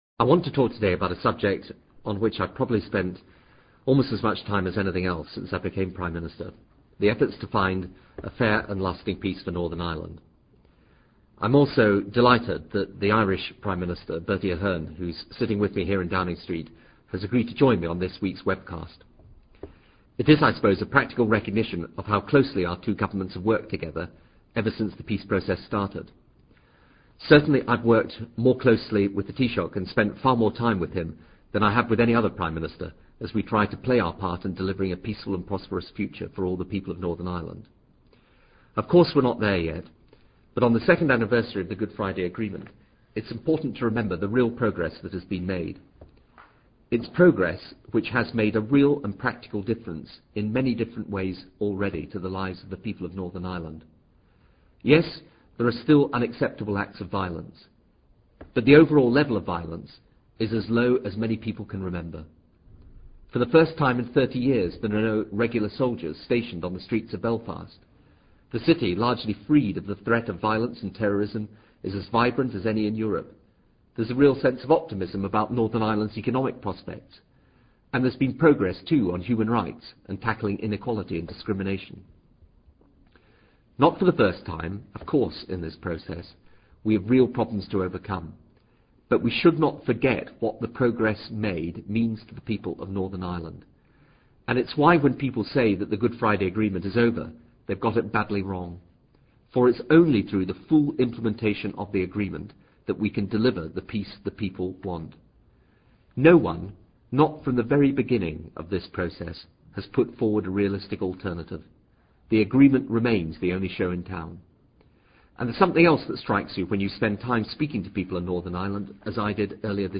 布莱尔首相演讲:Northern Ireland
Transcript by the Prime Minister and Taoiseach for Internet broadcast on Northern Ireland, 20/04/00